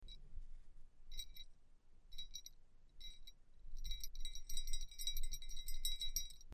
Sound recordings of original Roman bells from Vindobona (ancient Vienna) and Avar pellet bells from the cemeteries Csokorgasse 1110 Wien and Wien-Liesing (1230 Wien).
sound of orginal pellet bell grave 8 Wien-Liesing 0.1 MB